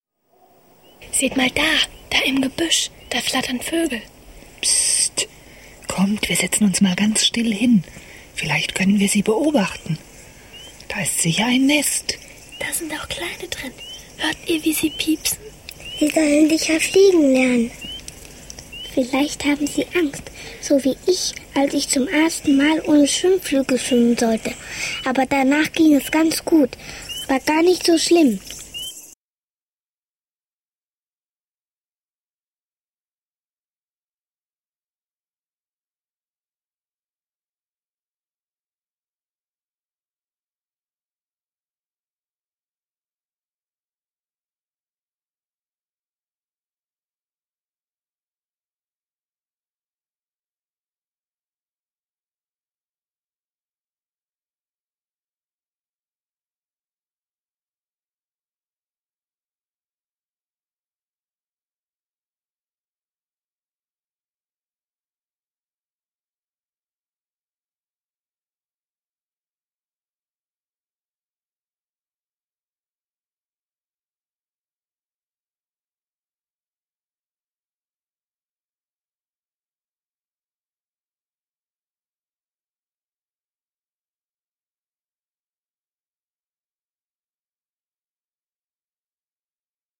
8. Fliegen lernen - Hörszene 4